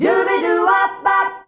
DUBIDUWA G.wav